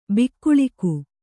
♪ bikkuḷiku